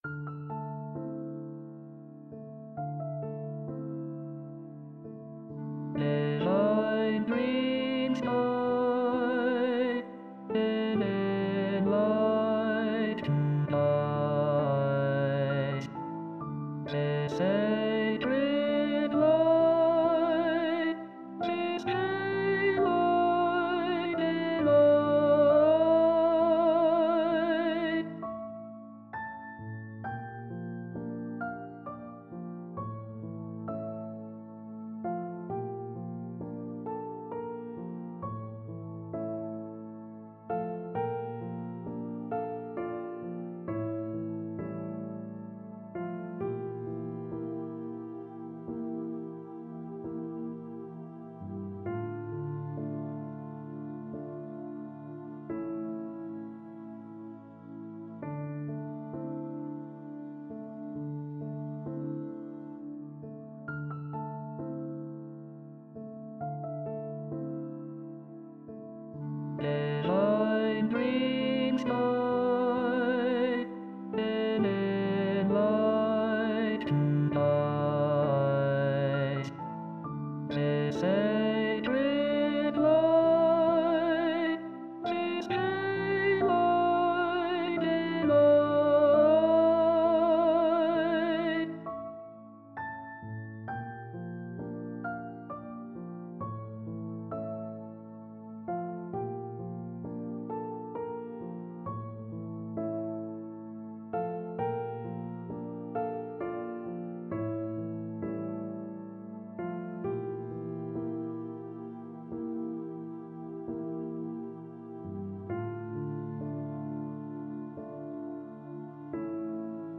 This is more like the latter, though I was going for something like a dreamy, mystical feeling since, well, it is called Divine Dreams.
∞ this version of the piece ∞, where a weird synthesized voice sings the lyrics. It's not exactly easy on the ears, but it's not like I was going to sing it myself, I was curious to see how the words would match the melody, and this was the best way of generating singing that I could find in the limited time I could be bothered devoting to it.
DivineDreamsMainThemeLyrics.mp3